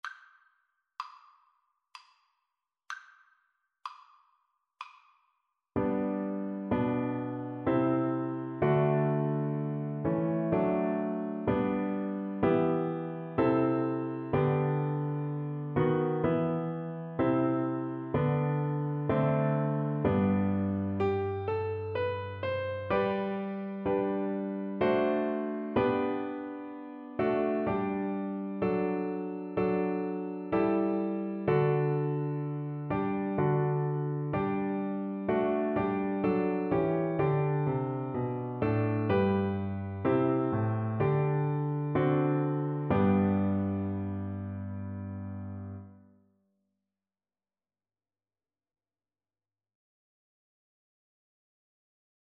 Play (or use space bar on your keyboard) Pause Music Playalong - Piano Accompaniment Playalong Band Accompaniment not yet available transpose reset tempo print settings full screen
Viola
G major (Sounding Pitch) (View more G major Music for Viola )
3/4 (View more 3/4 Music)
F#5-E6
Traditional (View more Traditional Viola Music)